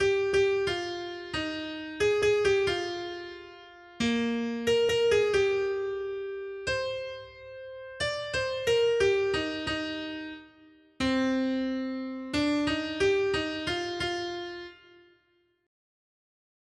responsoriální žalm